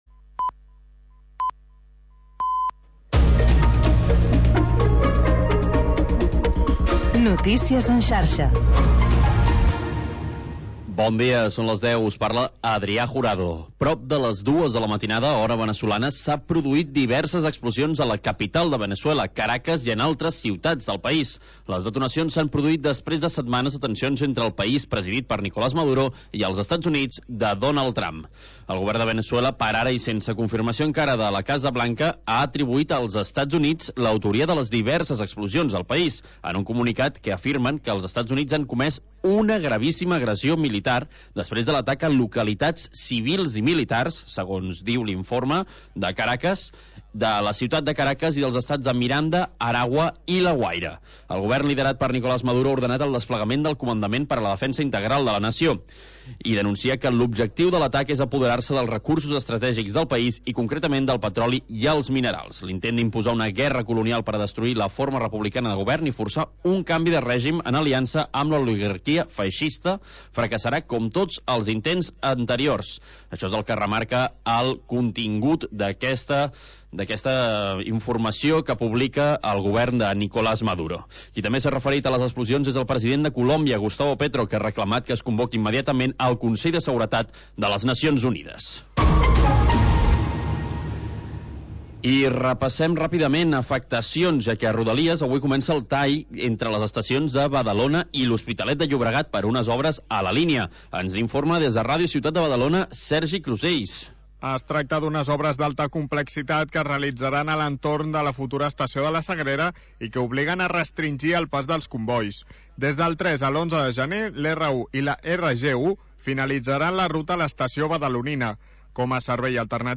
Programa sardanista, amb actualitat, compositors i agenda de ballades